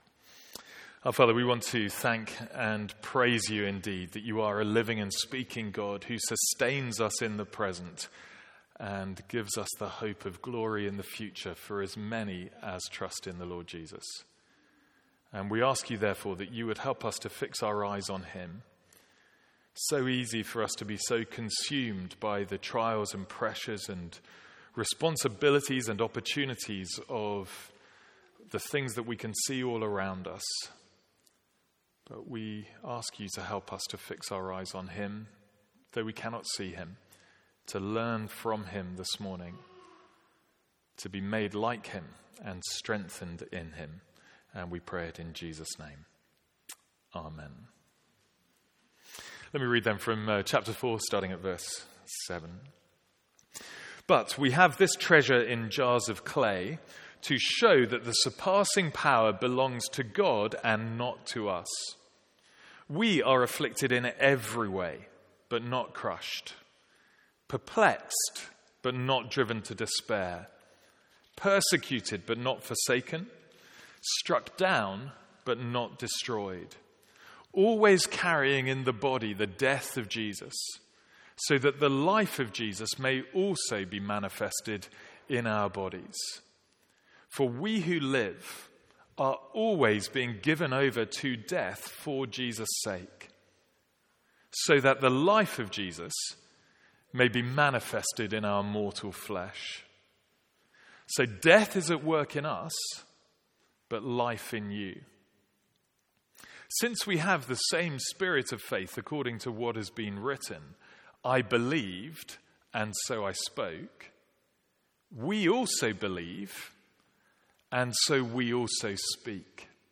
Sermons | St Andrews Free Church
From our morning series in 2 Corinthians.